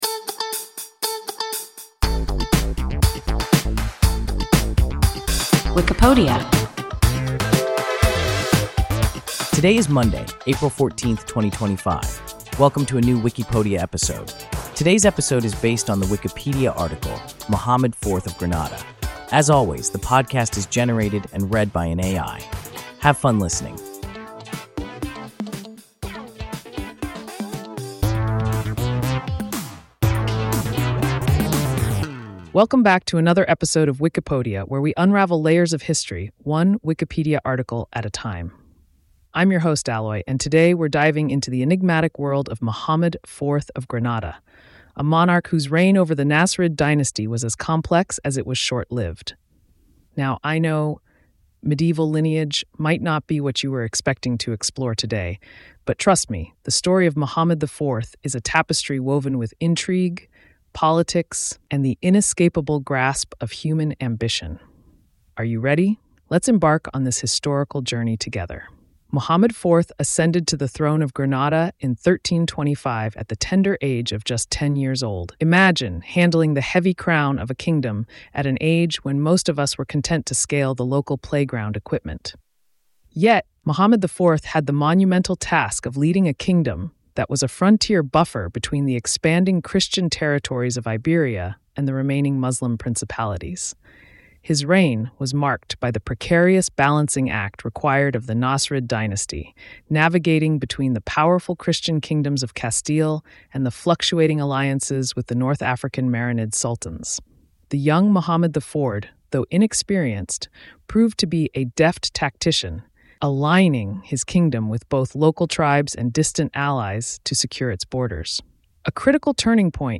Muhammad IV of Granada – WIKIPODIA – ein KI Podcast